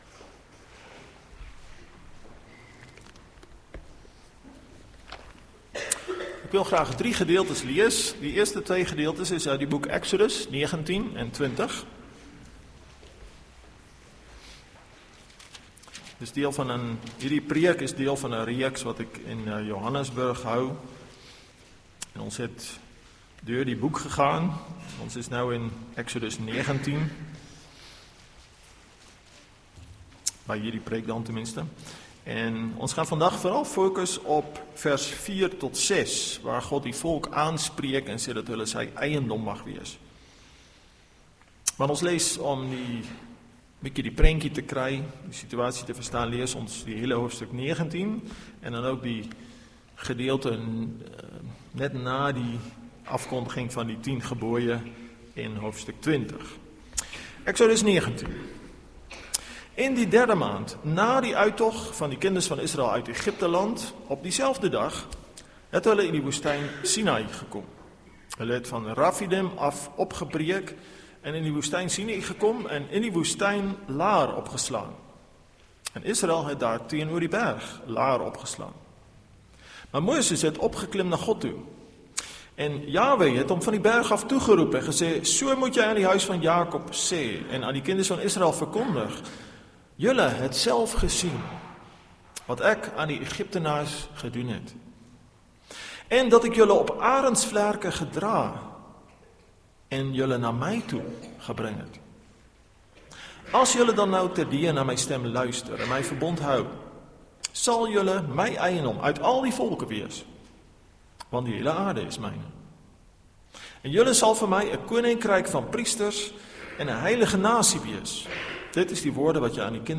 Link Preek Inhoud